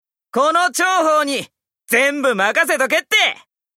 張苞（CV：阪口大助）